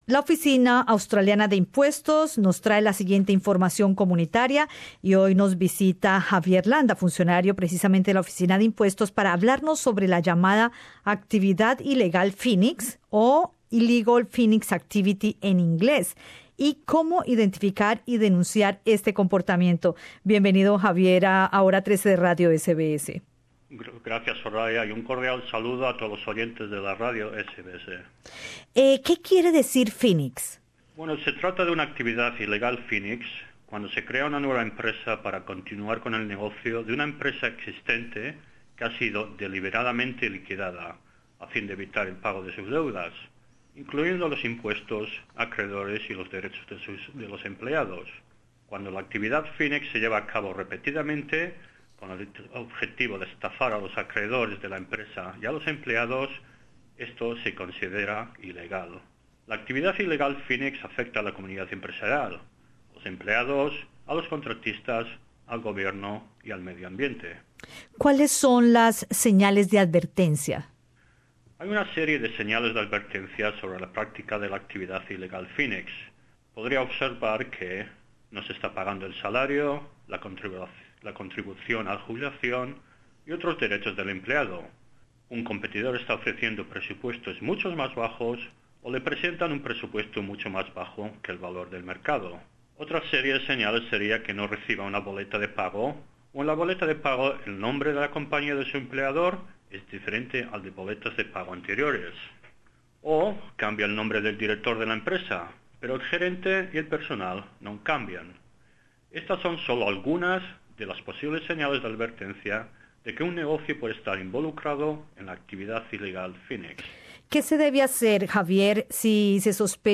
La siguiente información comunitaria es proveída por la Oficina Australiana de Impuestos, ATO. Entrevista